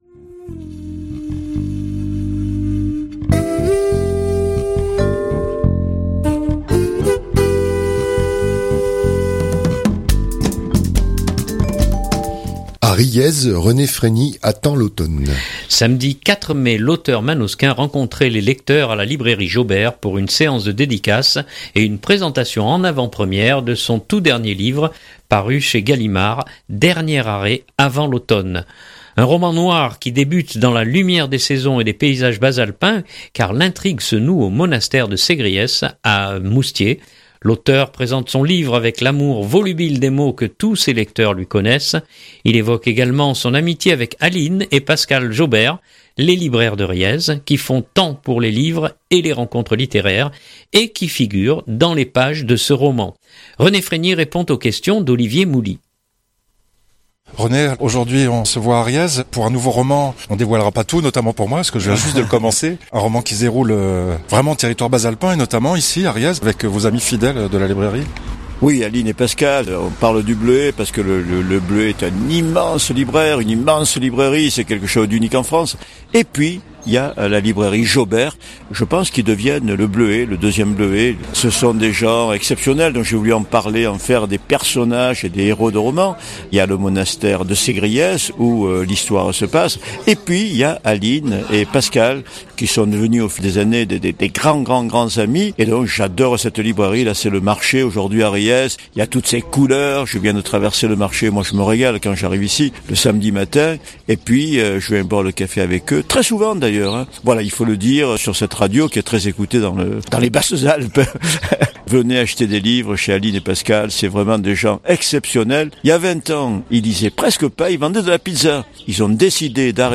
Un roman noir qui débute dans la lumière des saisons et des paysages bas-alpins car l'intrigue se noue au monastère de Ségriès à Moustiers. L’auteur présente son livre avec l'amour volubile des mots que tous ses lecteurs lui connaissent.